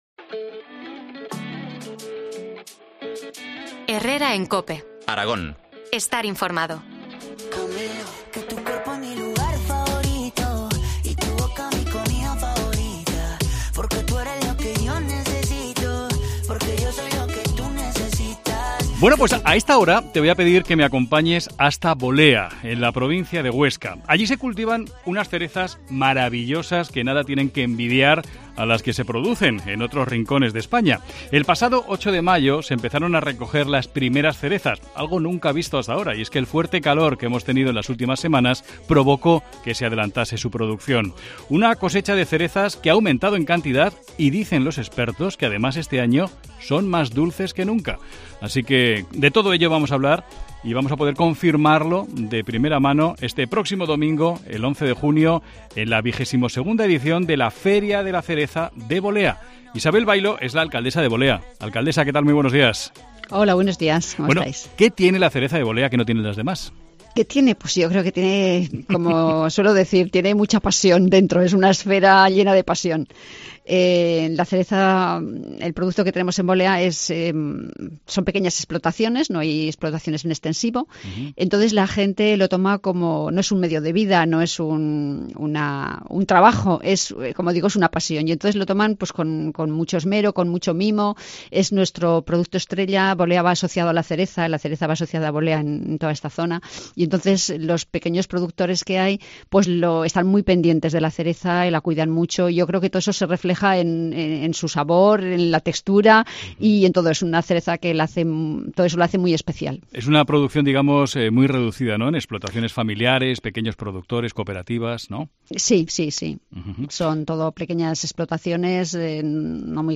Entrevista a la alcaldesa de Bolea, Isabel Bailo, con motivo de la XXII Feria de la cereza de Bolea.